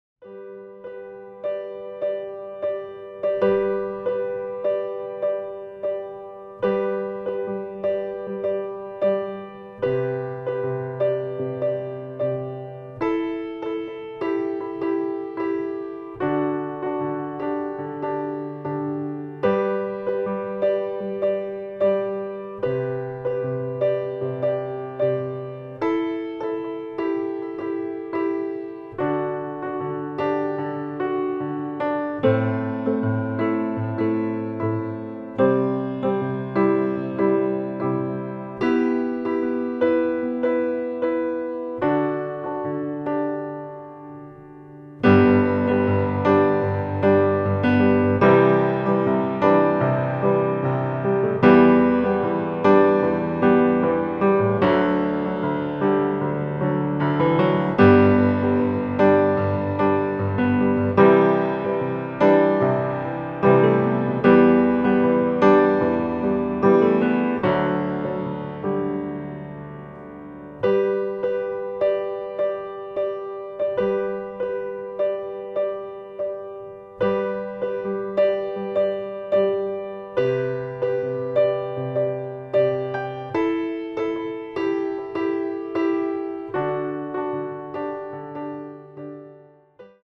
Klavierversion
• Tonart: G Dur
• Art: Flügel
• Das Instrumental beinhaltet keine Leadstimme
• Alle unsere DEMOS sind mit einem Fade-In/Out.
Klavier / Streicher